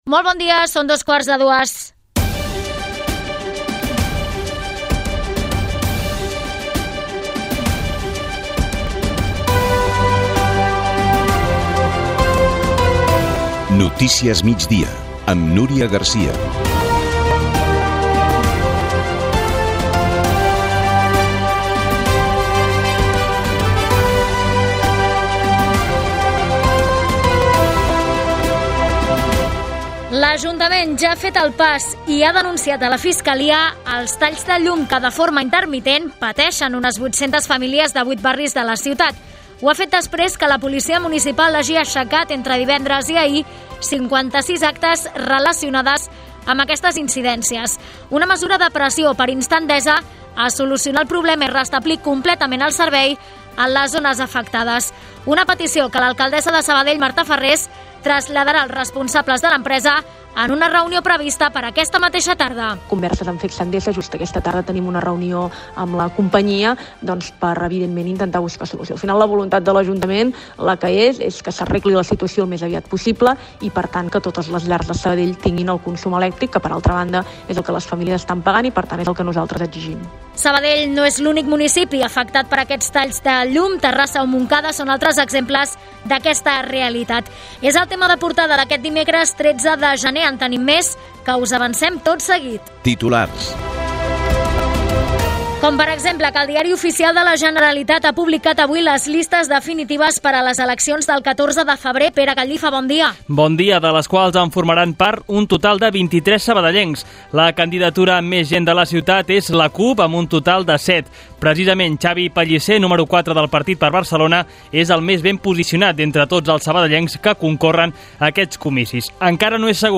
Hora, careta del programa, l'Ajuntament vol resoldre els talls de llum d'ENDESA. Titulars: properes eleccions al Parlament, estat de la Pandèmia de la Covid 19, nou jugador del Sabadell, indicatiu
Informatiu